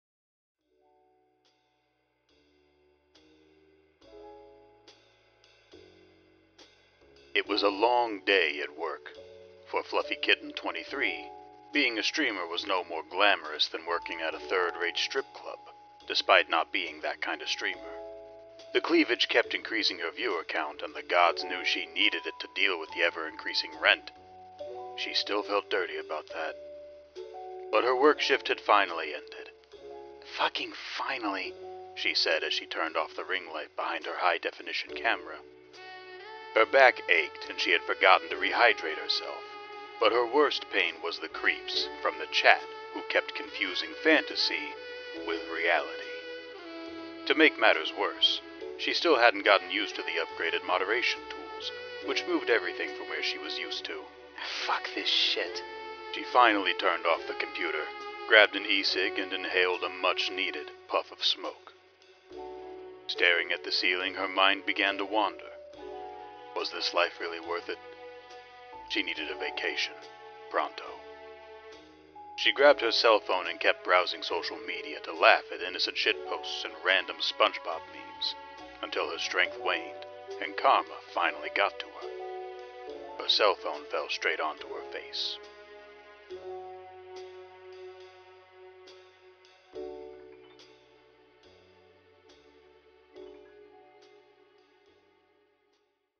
Music is "Murder on the Owl Express" from the soundtrack "A Hat in Time OST"